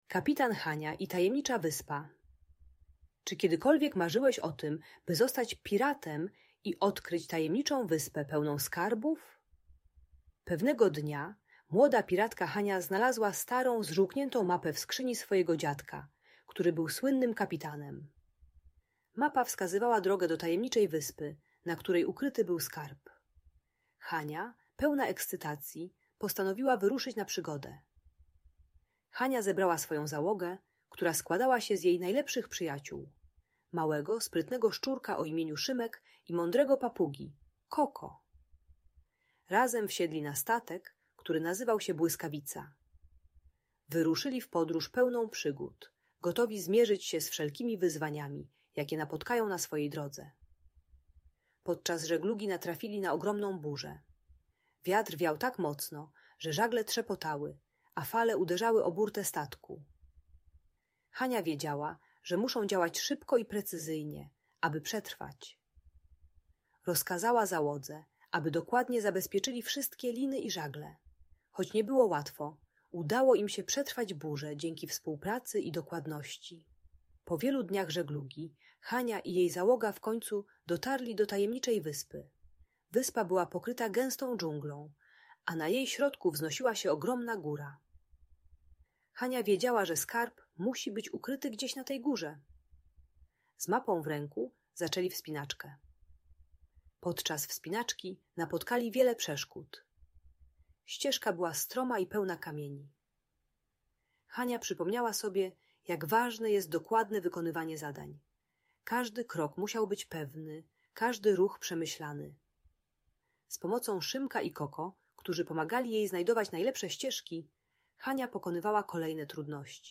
Kapitan Hania i Tajemnicza Wyspa - Niepokojące zachowania | Audiobajka